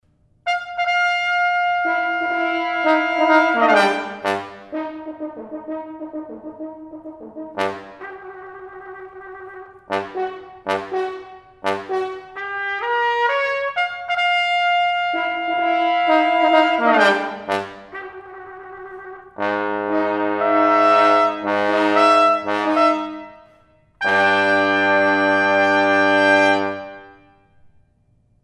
trumpet
trombone.